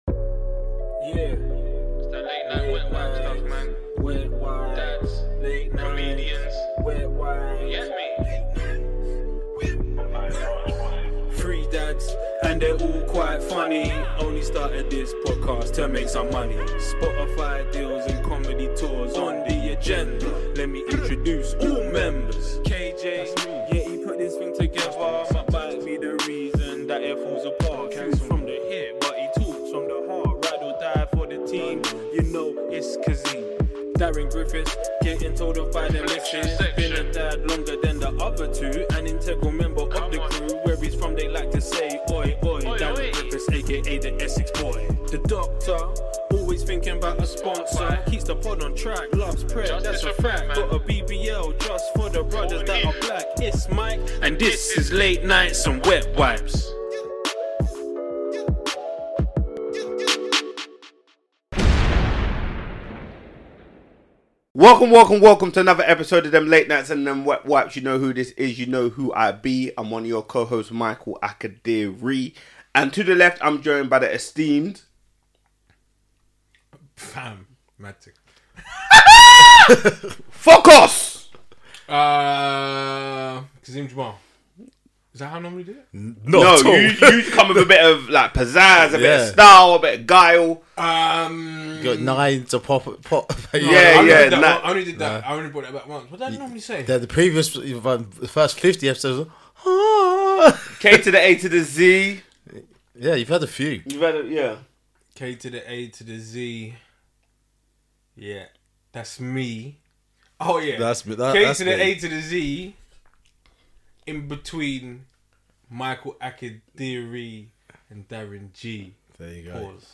… continue reading 99 episoade # Society # Parenting # Conversations # Comedy # Audioboom # Late Night # Wet Wipes